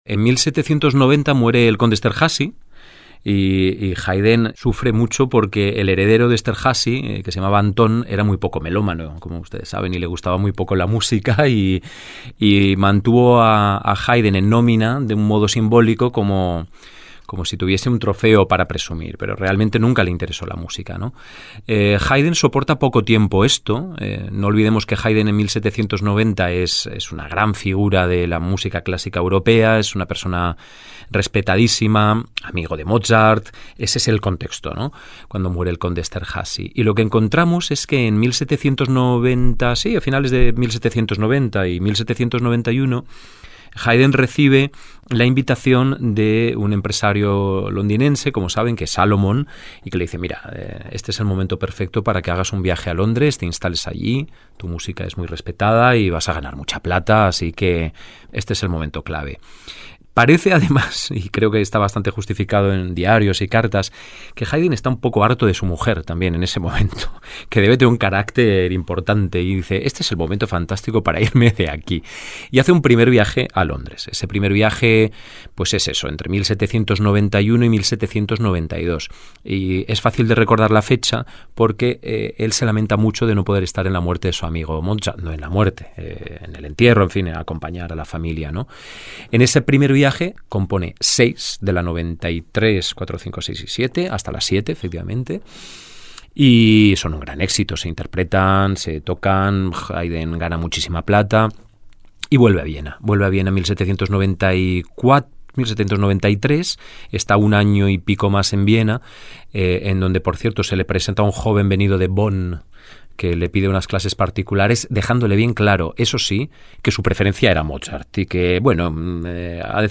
Compartimos la charla que mantuvimos a propósito del programa y su vínculo con Uruguay: